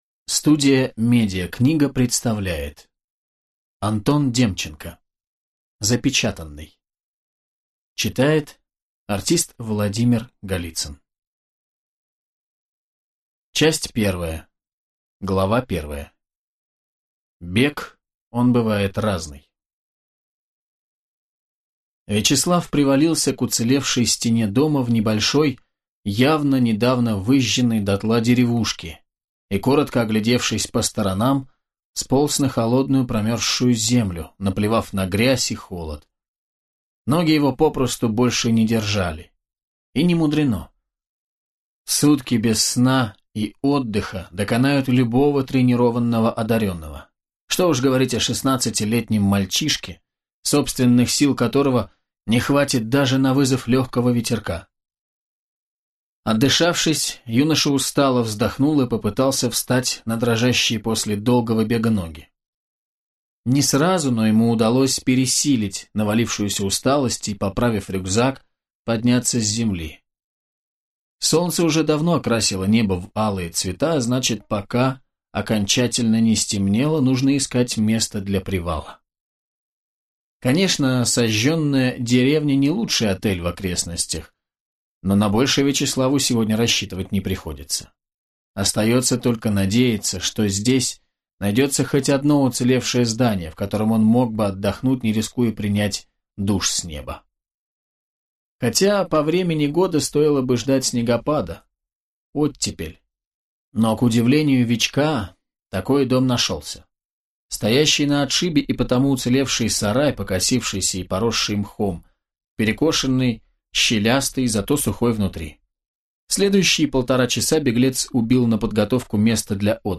Аудиокнига Воздушный стрелок. Запечатанный | Библиотека аудиокниг